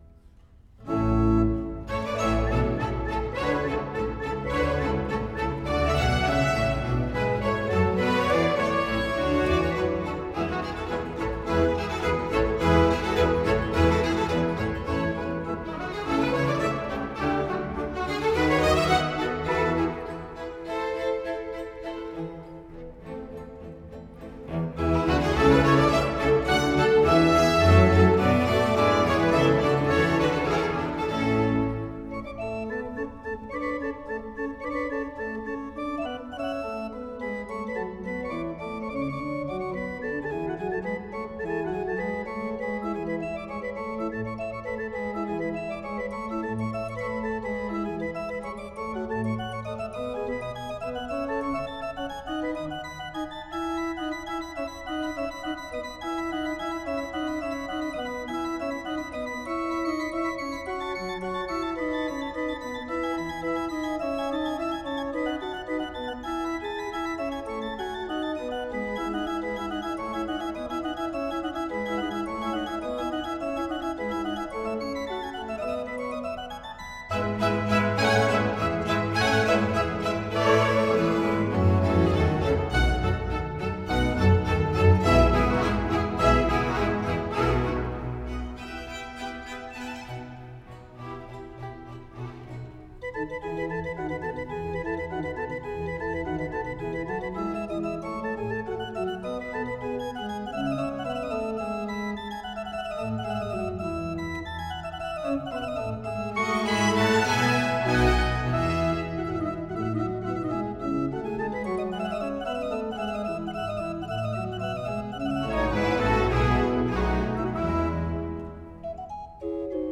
Organ Concerto in B-flat major, Op.4 No.2 - II. Allegro